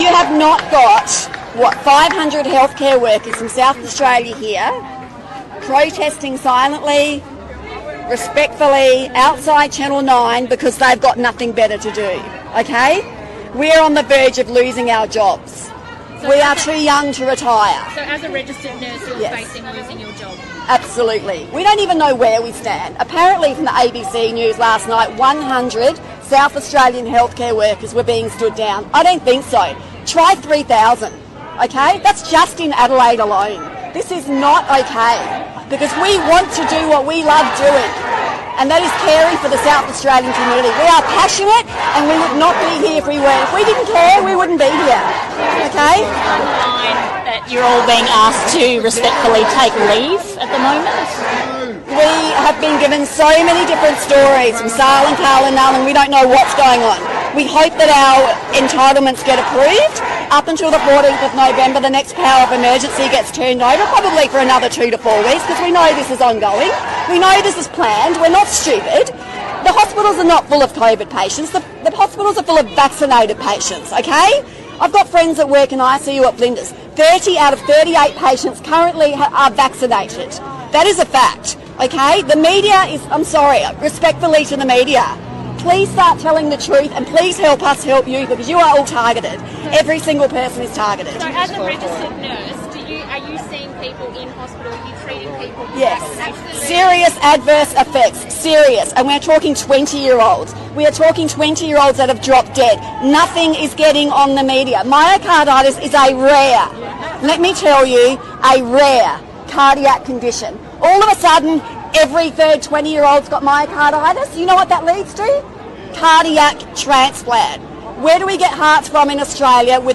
Australian nurse drops some truth bombs about what is going on